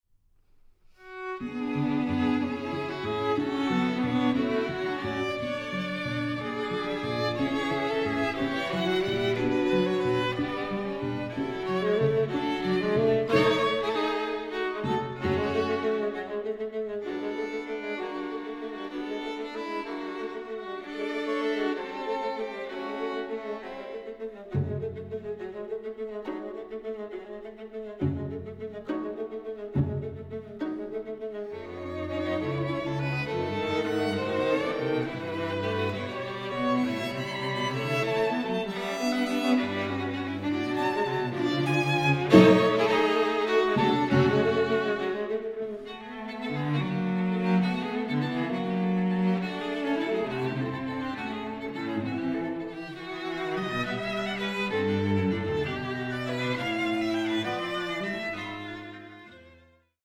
String Quartet in D major
II. Allegretto volando